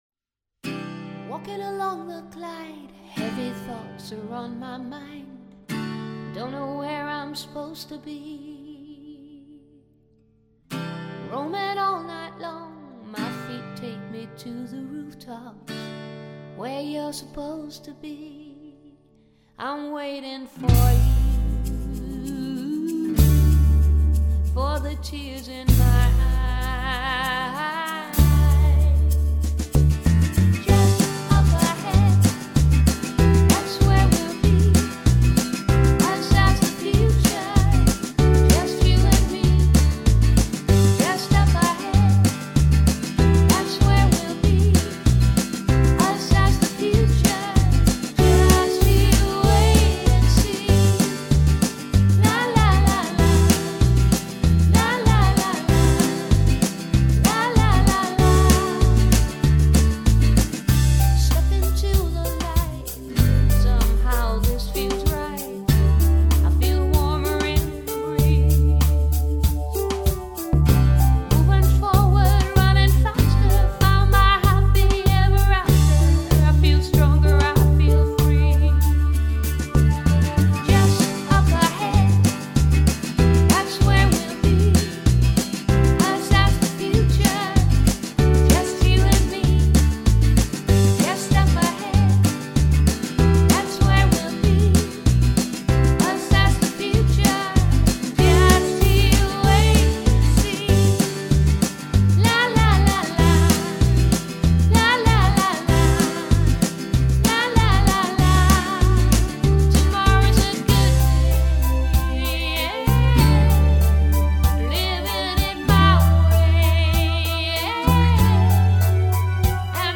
Over the past 3 years the residents have participated in drama, creative writing, film-making, visual art and song-writing and recording.
These are some of the tunes created for our most recent project.